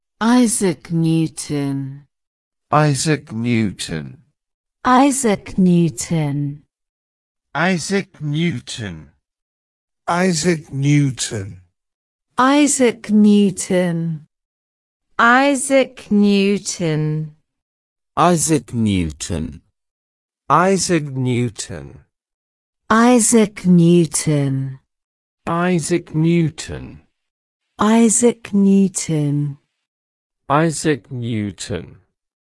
Pronunciation-isaac-newton.ogg